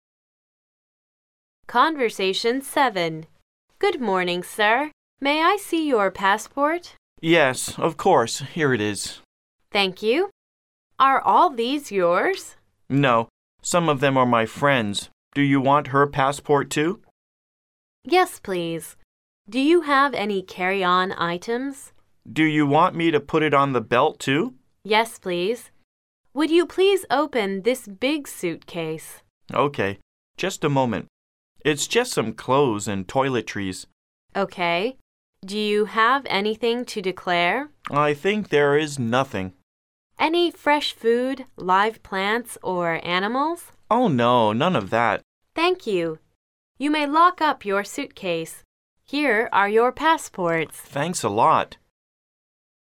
Conversation 7